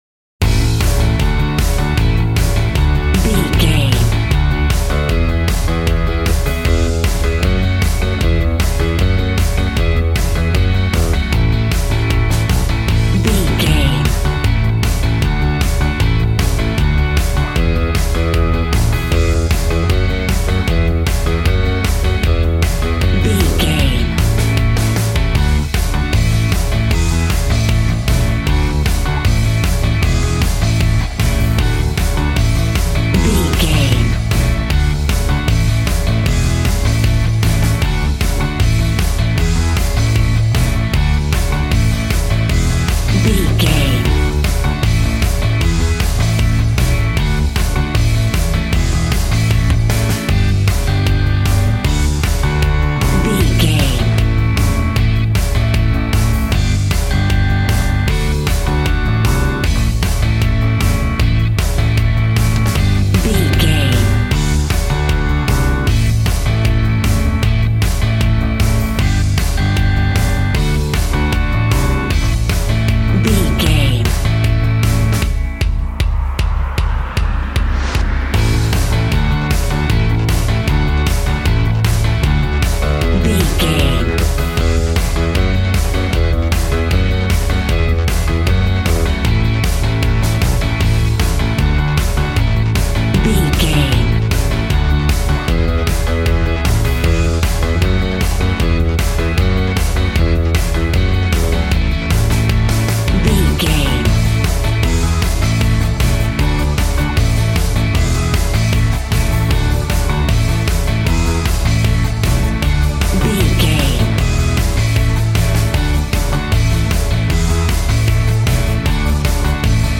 Ionian/Major
groovy
happy
electric guitar
bass guitar
drums
piano
organ